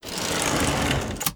door_close.wav